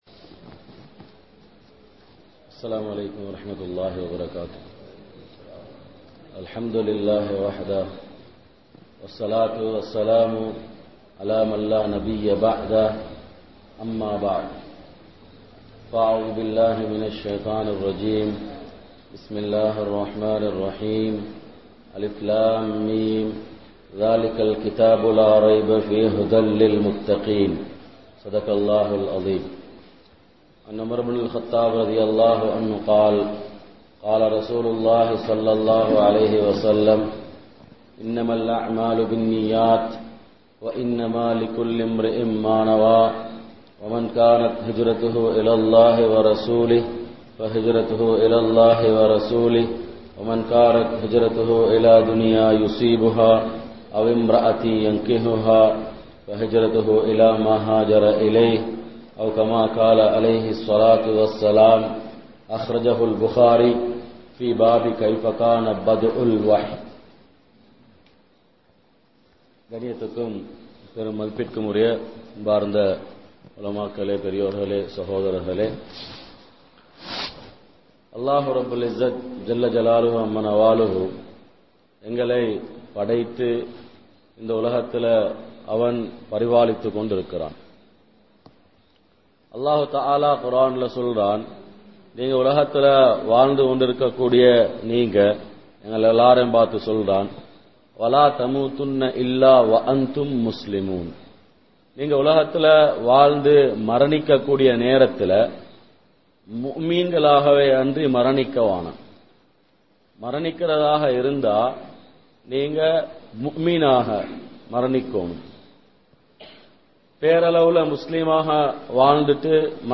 Padaiththavanai Marantha Samooham (படைத்தவனை மறந்த சமூகம்) | Audio Bayans | All Ceylon Muslim Youth Community | Addalaichenai